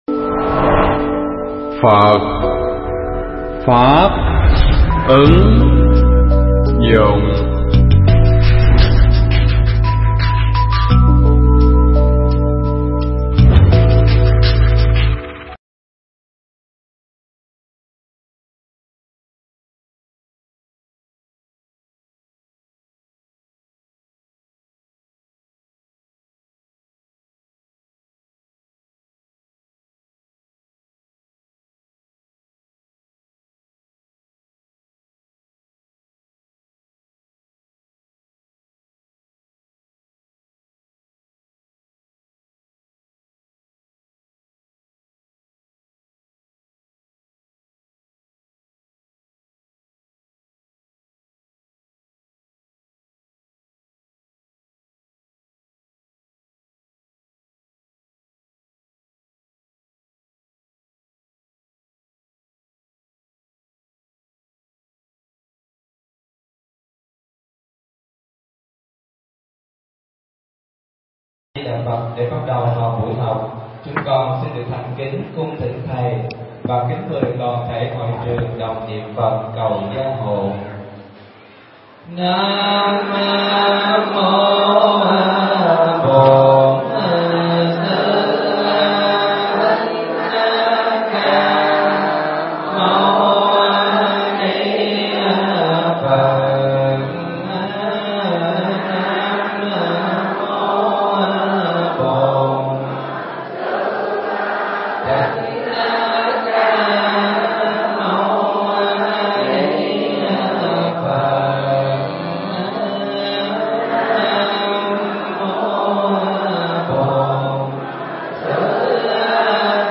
Mp3 Pháp thoại Kinh Pháp Cú Phẩm Đao Trượng
thuyết pháp tại tu viện Tường Vân